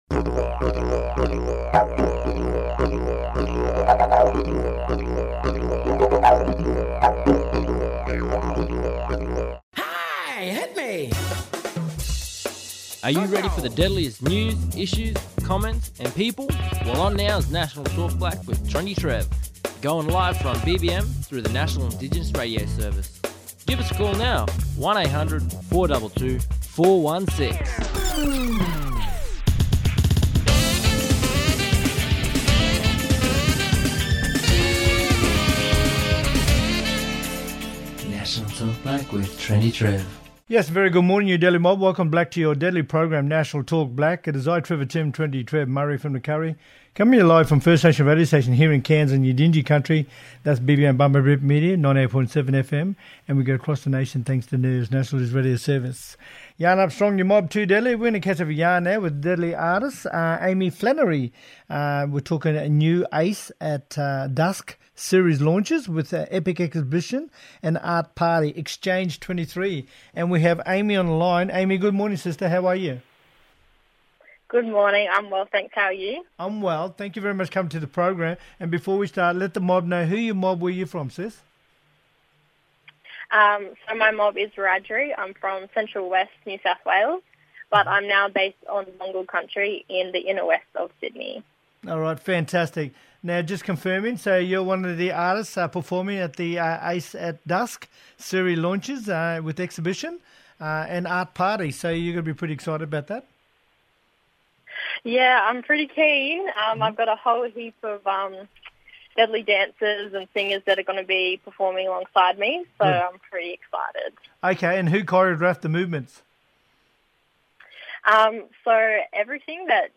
Mayor Phillemon Mosby, Torres Strait Island Regional Council Mayor, talking about TSIRC to redeclare Masig Statement on 86th Anniversary of local government. Last month, eleven Torres Strait Island Regional Council outer island communities gathered at local events to commemorate the 86th Anniversary of the First Councillors Meeting and reaffirm their commitment to the Masig Statement.